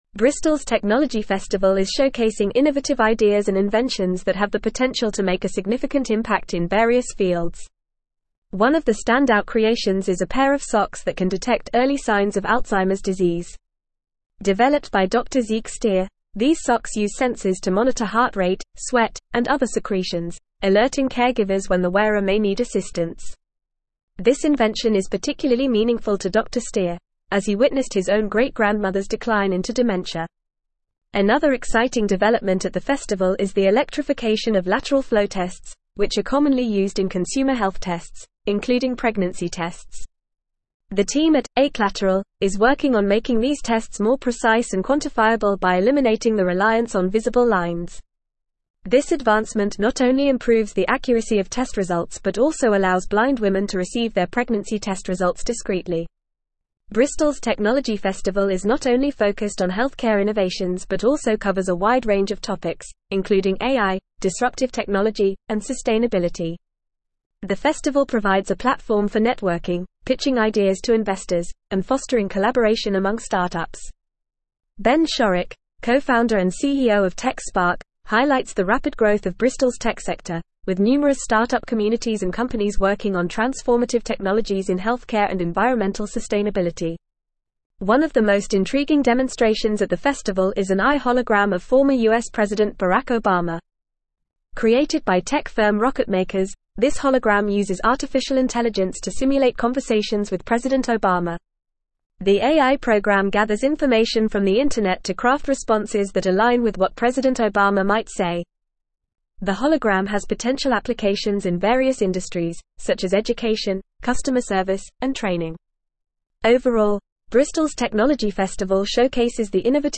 Fast
English-Newsroom-Advanced-FAST-Reading-Bristols-Tech-Festival-Showcases-Innovative-Ideas-and-Inventions.mp3